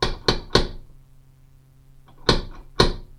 Tags: scary